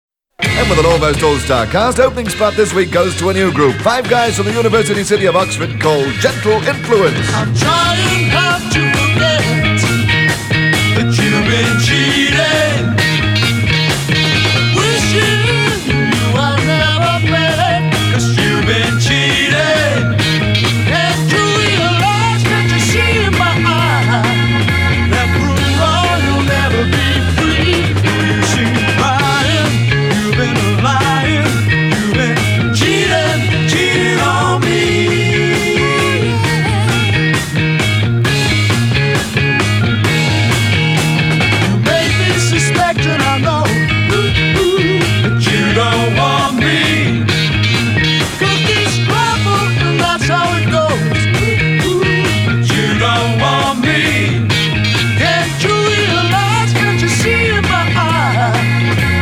Recorded iat BBC Studios London Spring 1969.